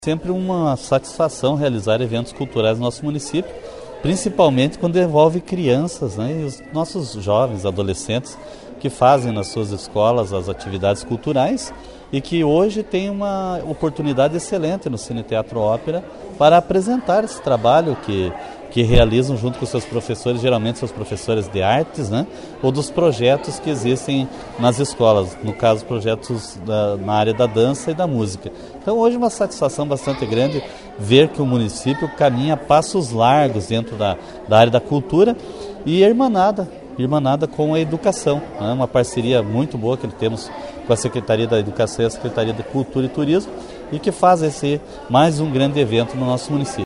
O secretário Municipal de Cultura e Turismo de Porto União, José Carlos Gonçalves, falou sobre a programação do aniversário de Porto União e a Secretaria de Educação da cidade que está fazendo a sua parte como todos os anos, com a “Noite de Artes”.